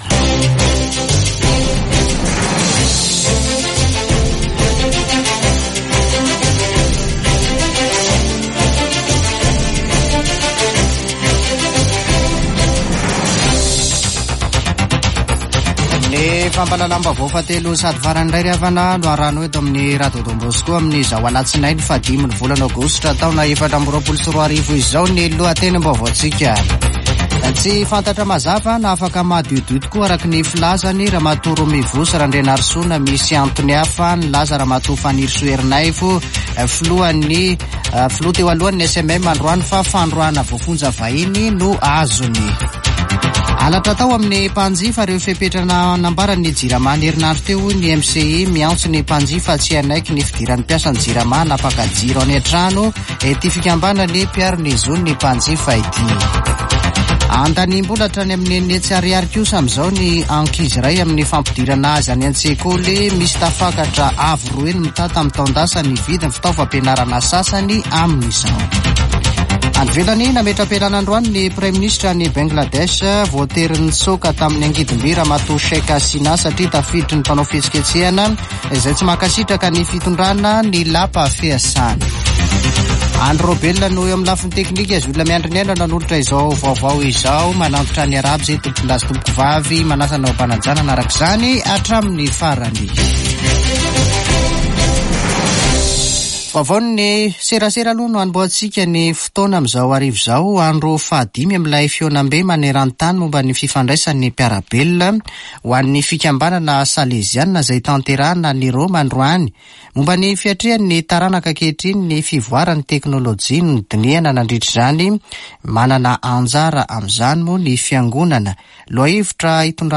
[Vaovao hariva] Alatsinainy 5 aogositra 2024